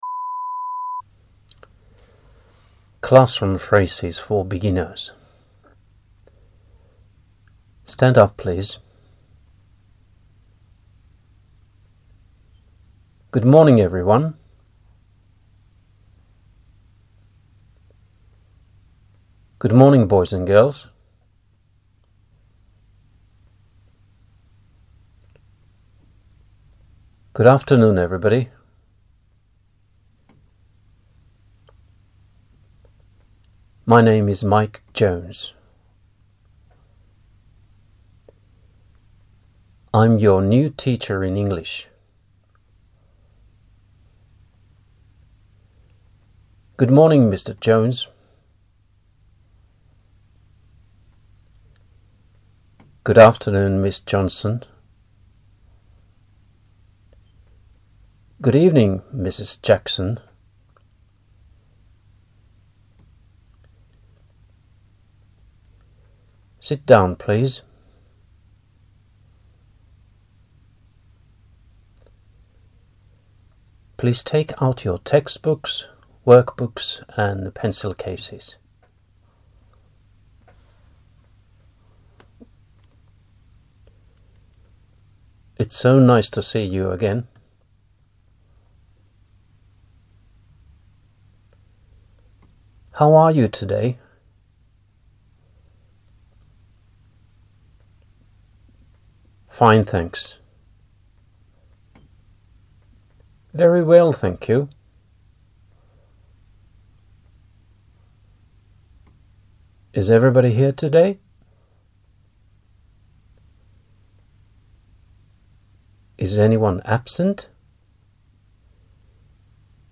The phrases are recorded with pauses.